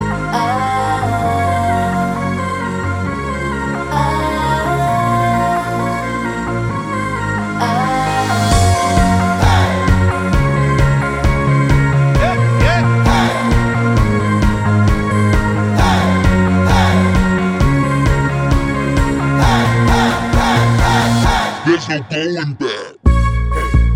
For Solo Female Pop (2010s) 3:02 Buy £1.50